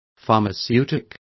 Complete with pronunciation of the translation of pharmaceutic.